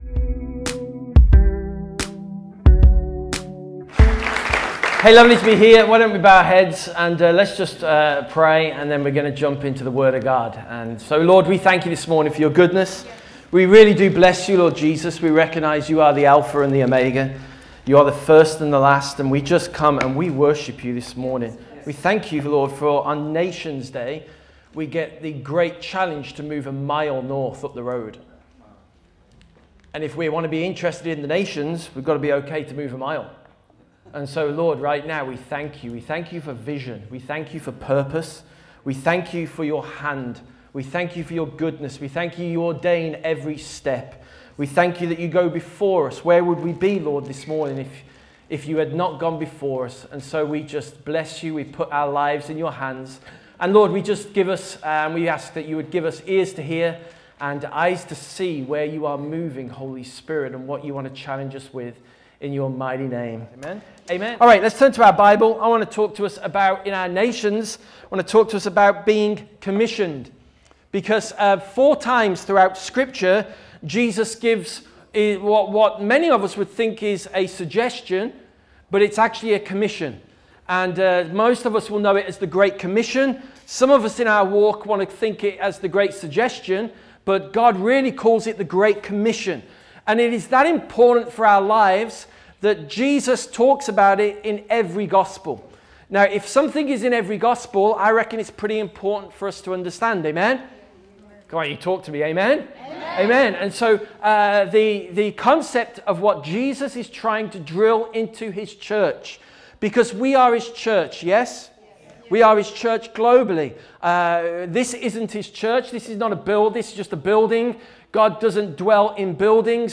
Sunday Messages The Great Commission